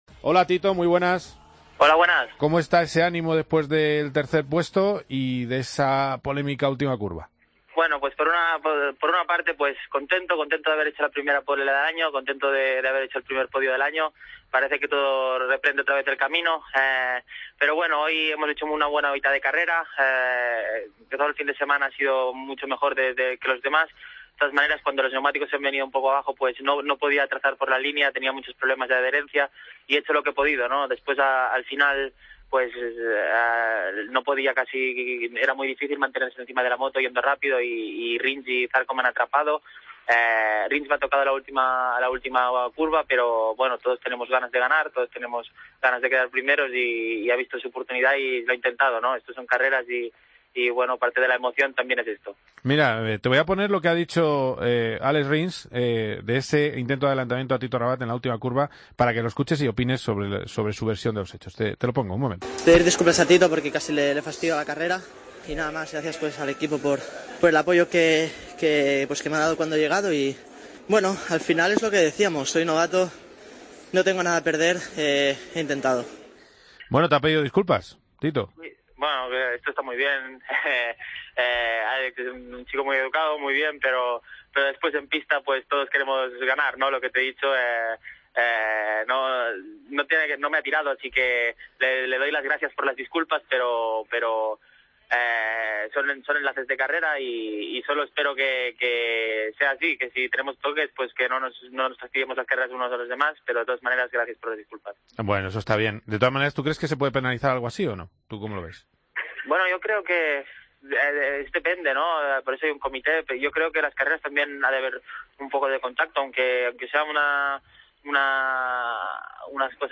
Hablamos en COPE GP con Tito Rabat, actual campeón de Moto2 y tercero este domingo en Jerez.